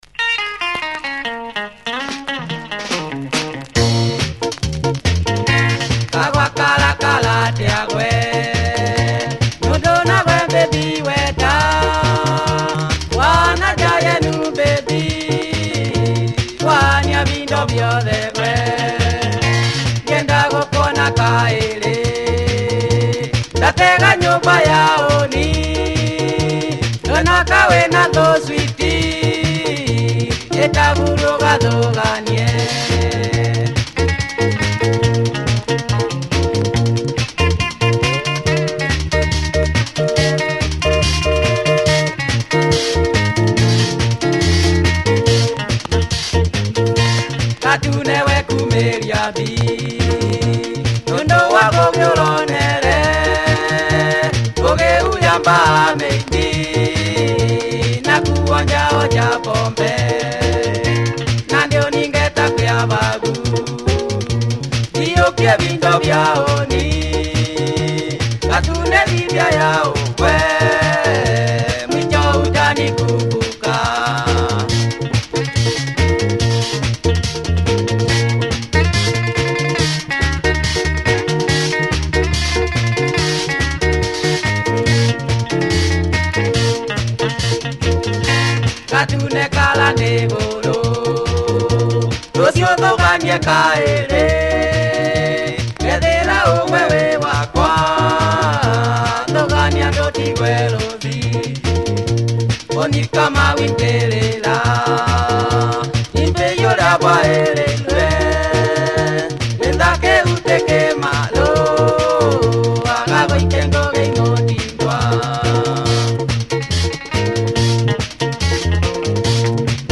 Killer funky Kikuyu benga drive
big dancefloor spin.